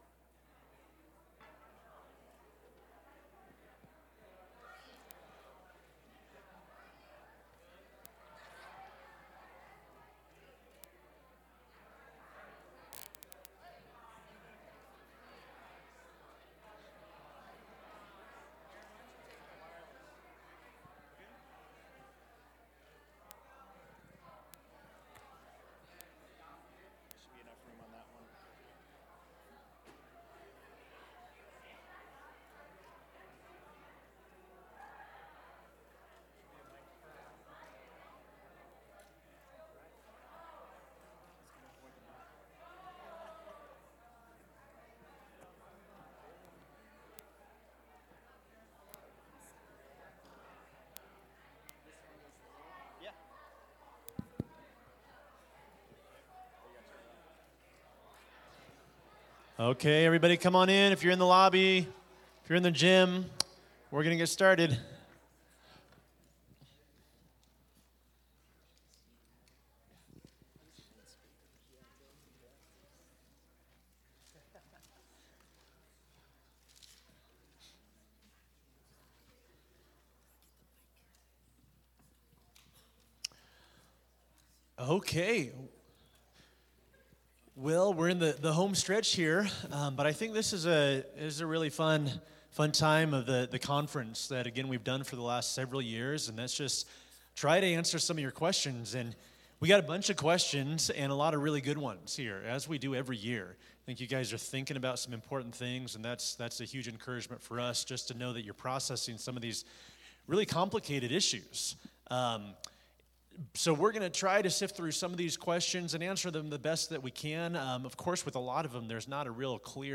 Teen-Conference-QA.mp3